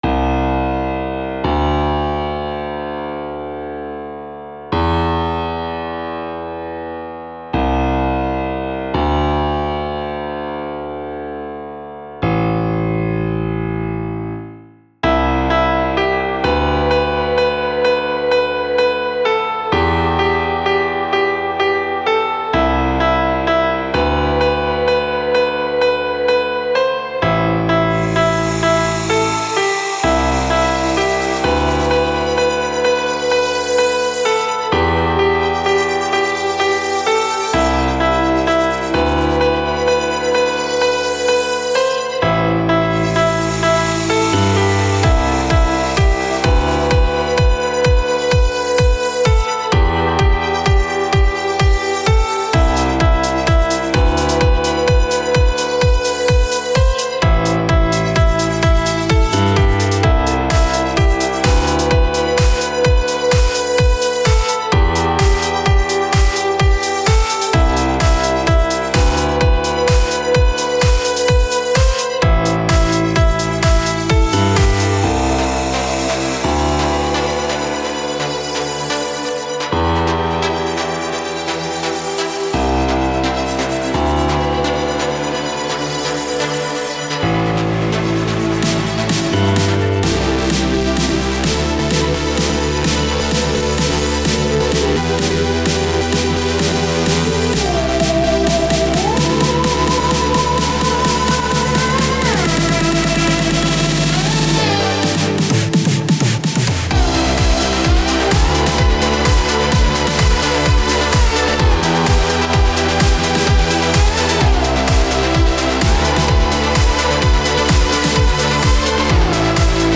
Сэмплы: Все свои, кроме двух FX.